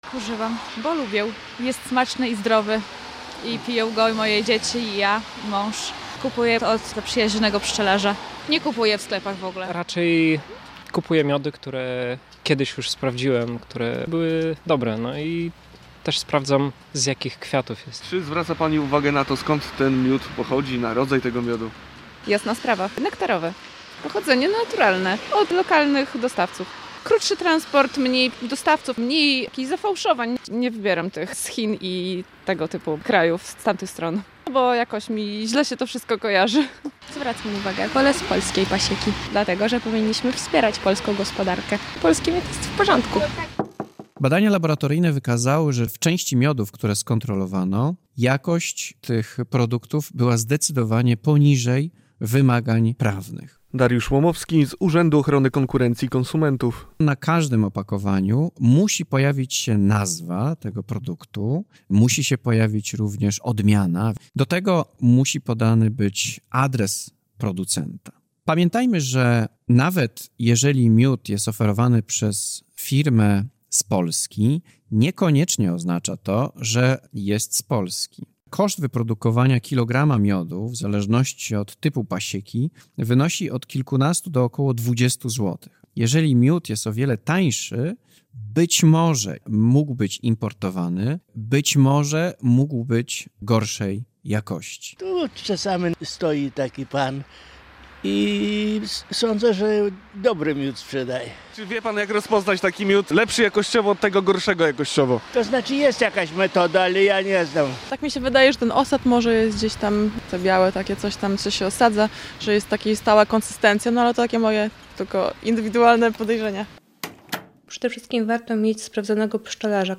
Czy kupujemy miód dobrej jakości? - relacja